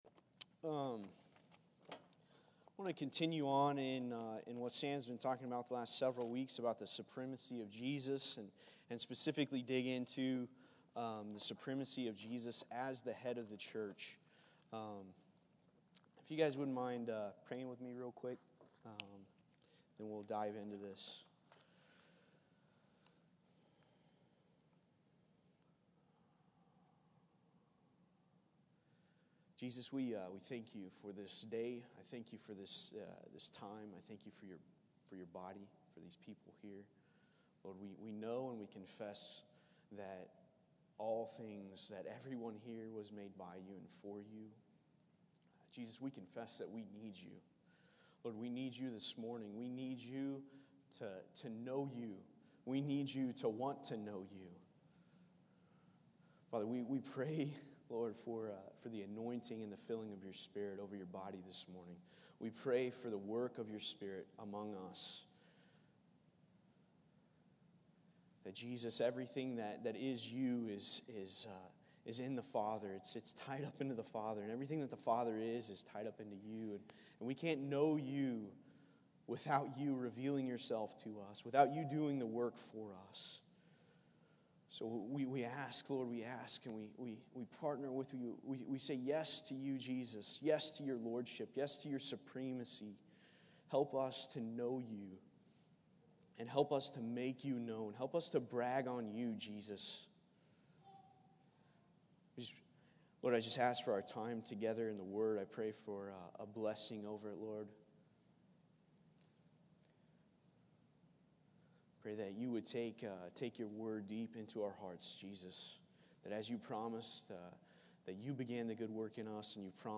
preaches on the church being the body of Jesus who is the head.